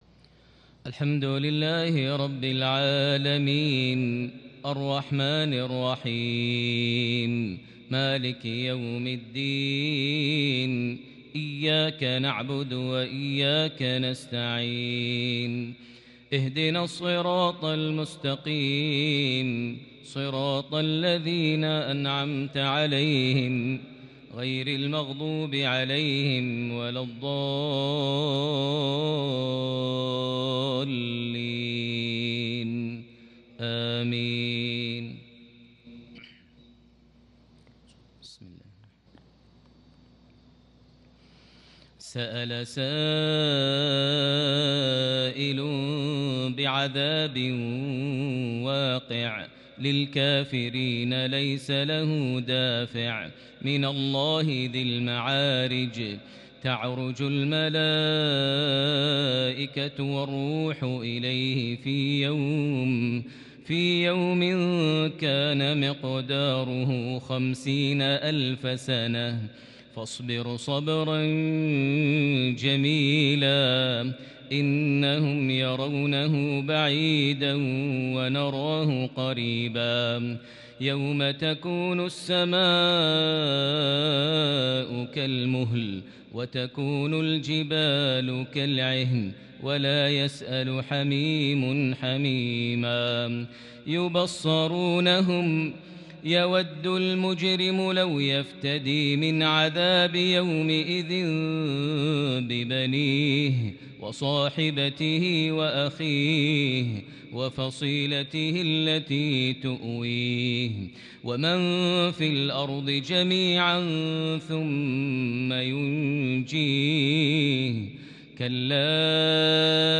صلاة الفجر ٩ شوال ١٤٤١هـ سورة المعارج > 1441 هـ > الفروض - تلاوات ماهر المعيقلي